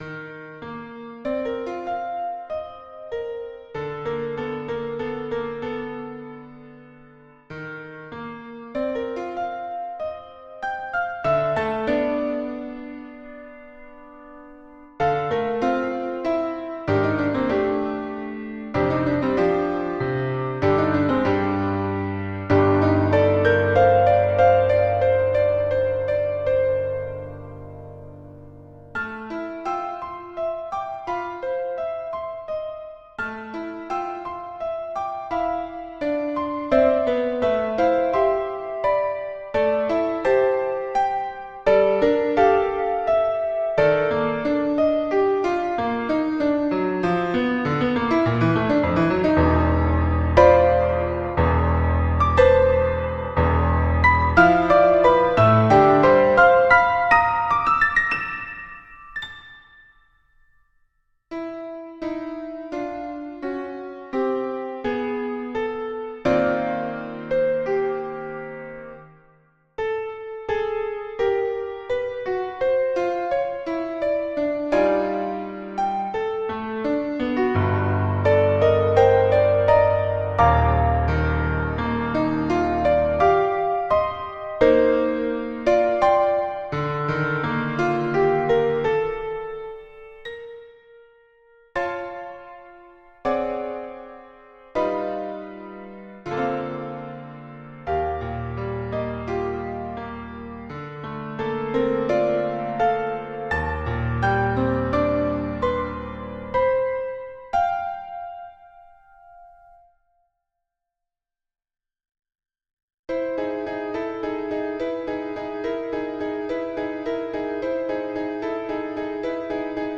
MIDI mp3 file
Instrumental